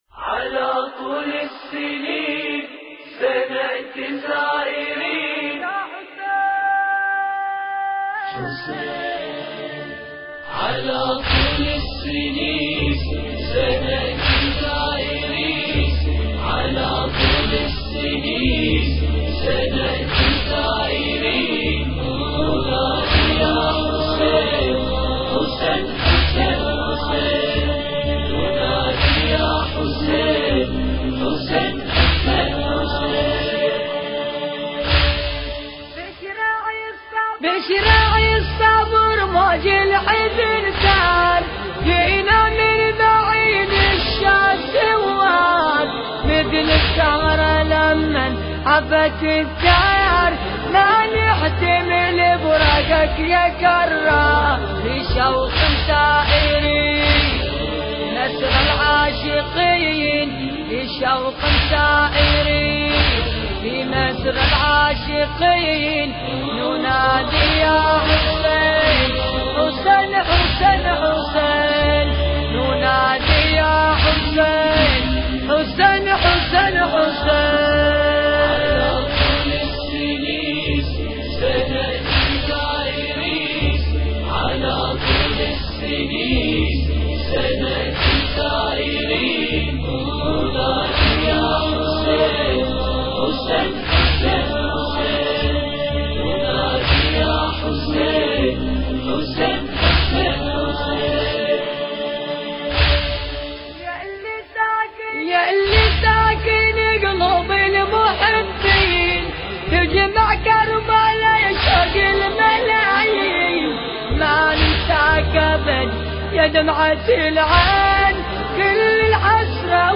المراثي